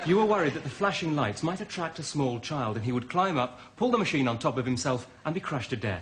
Coffee Machine